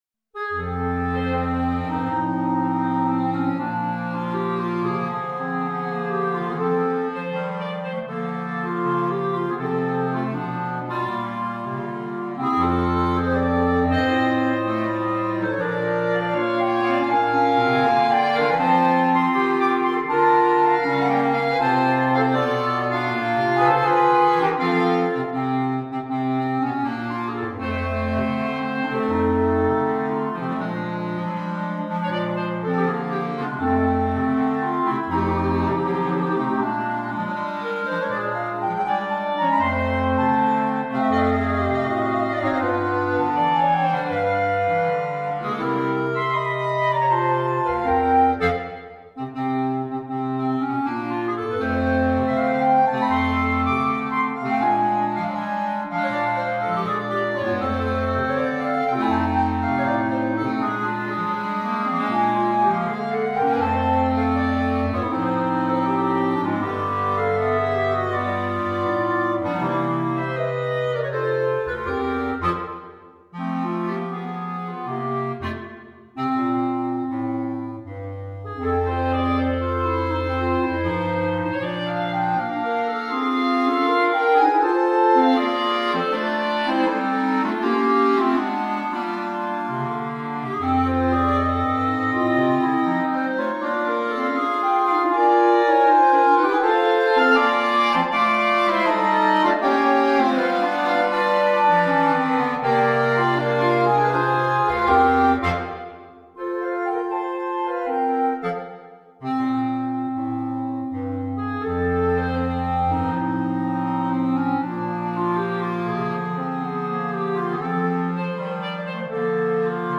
Per coro di clarinetti